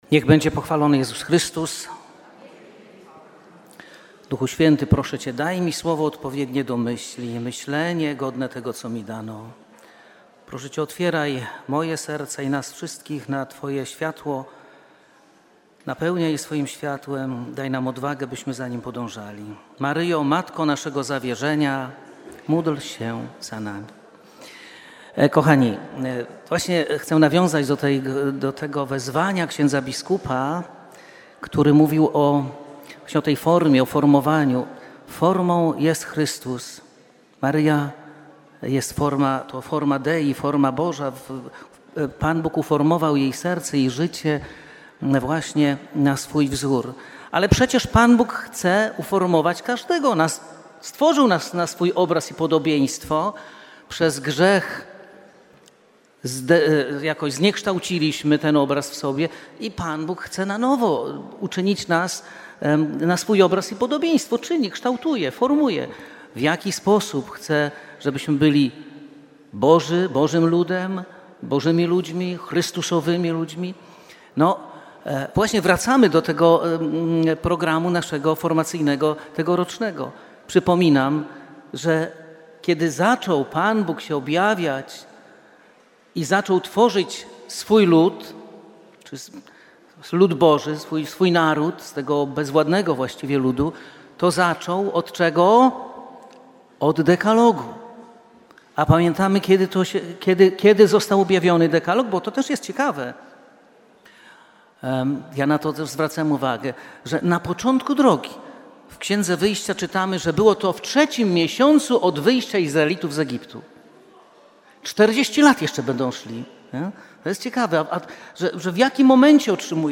Dalsza cześć spotkania odbyła się w sali o.Kordeckiego.
Nagranie z Radia Jasna Góra